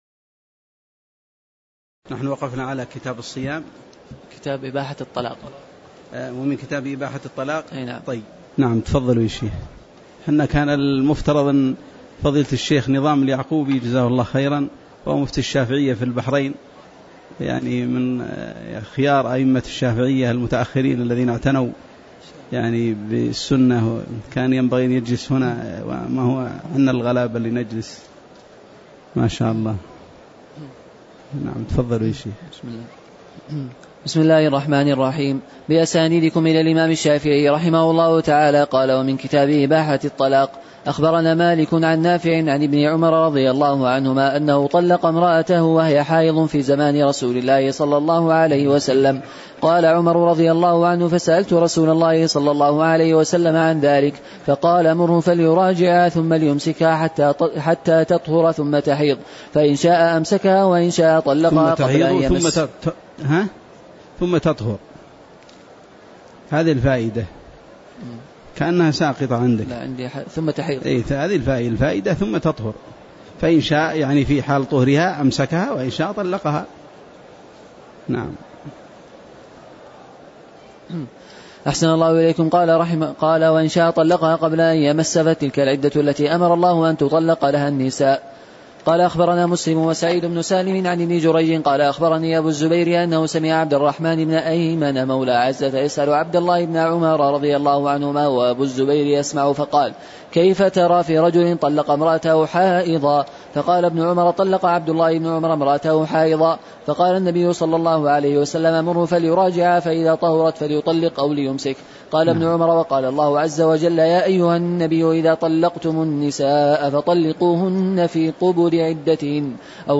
تاريخ النشر ١٣ جمادى الآخرة ١٤٣٨ هـ المكان: المسجد النبوي الشيخ